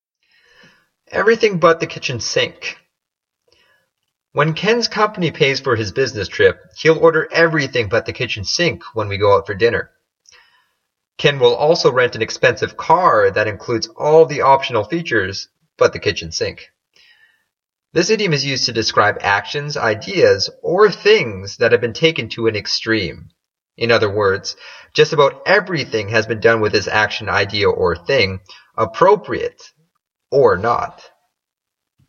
英語ネイティブによる発音 は下記のリンクをクリックしてください。